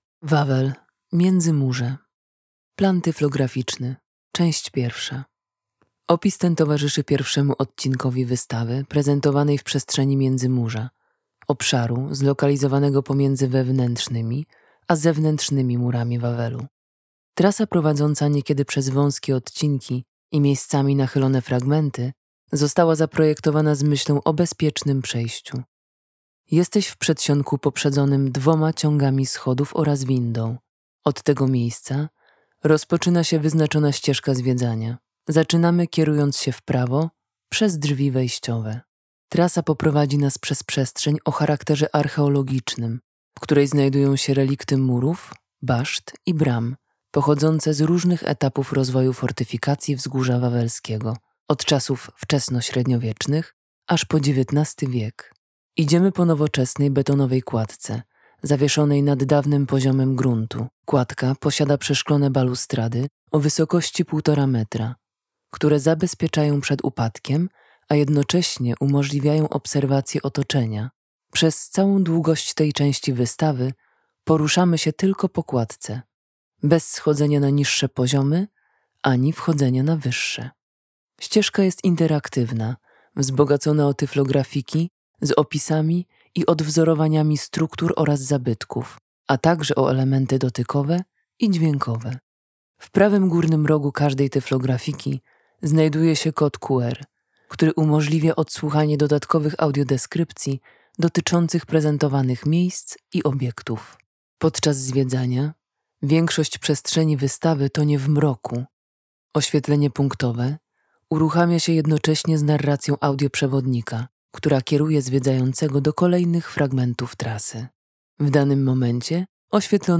Audiodeskrypcja planu wystawy, część 1 Plan wystawy, część 2